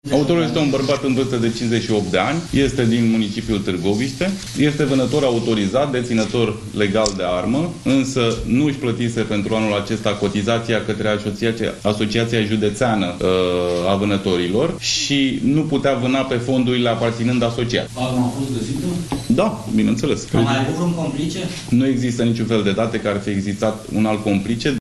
Suspectul în cazul dublei crime de la Târgoviște este audiat în această seară și va fi reținut, anunță prim procurorul Parchetului de pe lângă Tribunalul Dâmbovița, Mihai Dincă.